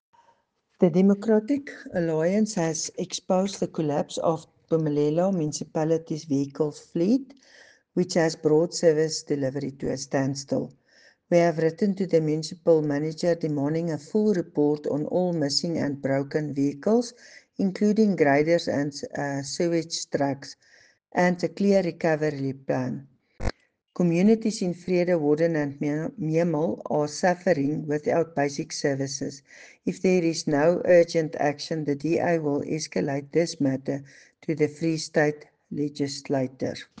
Afrikaans soundbites by Cllr Doreen Wessels and